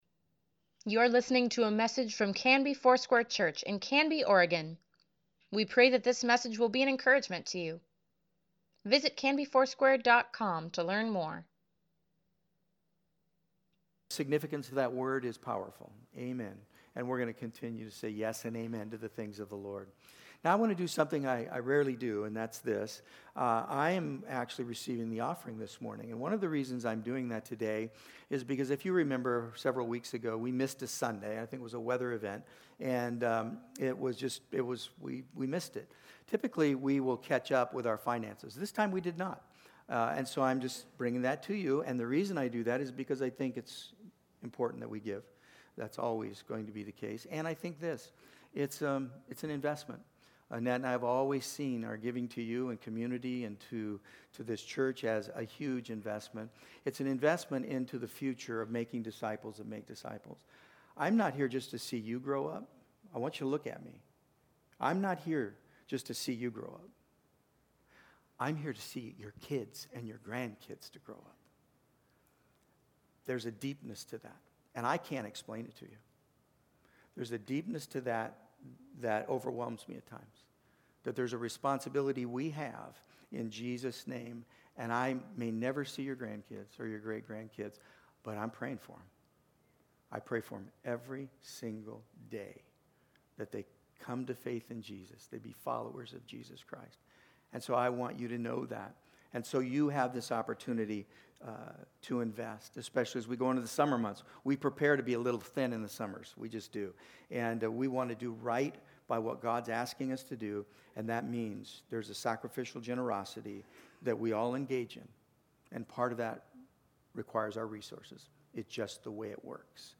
Sunday Sermon | April 14, 2024